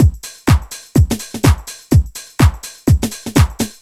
Index of /musicradar/retro-house-samples/Drum Loops
Beat 11 Full (125BPM).wav